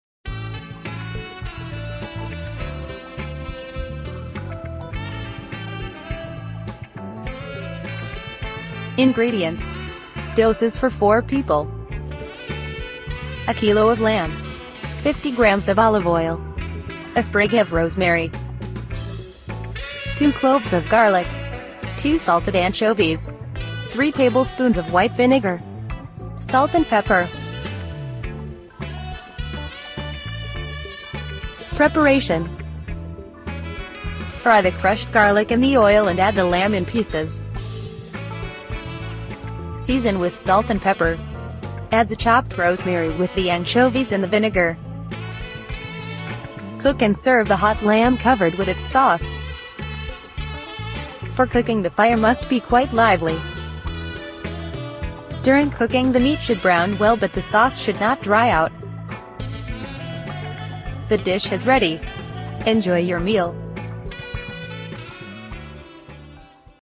Con uno stile diretto, popolare ma mai banale, Abbacchio alla Romana racconta la città eterna e chi la vive, tra romani veraci, nuovi cittadini e personaggi fuori dagli schemi. Non importa se sei nato sotto il Cupolone o sei solo di passaggio: qui si chiacchiera come a tavola, con una carbonara sul fuoco e il mondo da commentare.